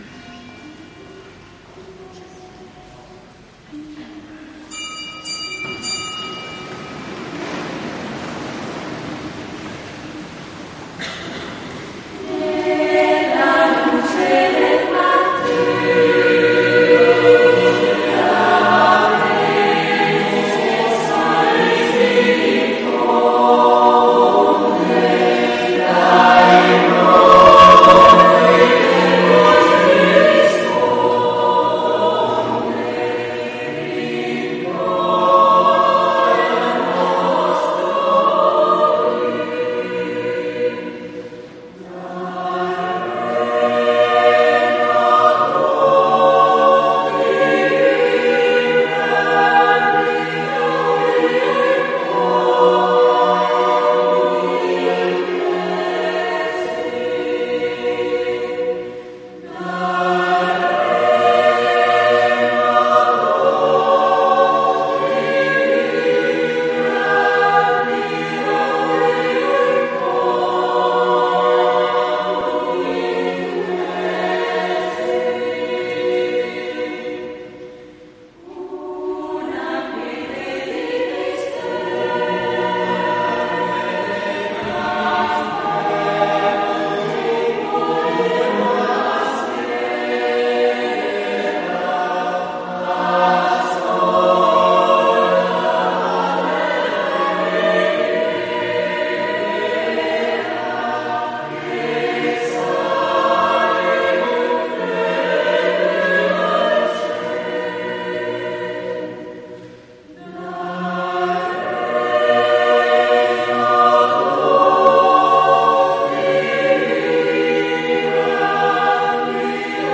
LA MESSA PER I CADUTI DELLA MONTAGNA ACCOMPAGNATA DAL CORO AGORDO
AGORDO Grande partecipazione come ogni ultima domenica di ottobre nell’Arcidiaconale di Agordo per la messa ai Caduti della montagna organizzata e cantata dal Coro Agordo e trasmessa in diretta a RADIO PIU’.
AUDIO INTEGRALE, MESSA IN DIRETTA ALLA RADIO
messa-caduti.mp3